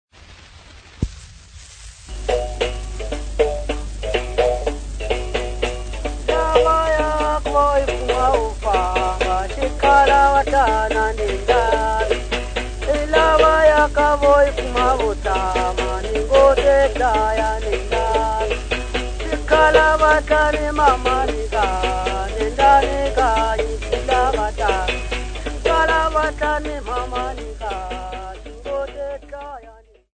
Popular music--Africa
Field recordings
Africa Mozambique City not specified f-mz
sound recording-musical
Indigenous music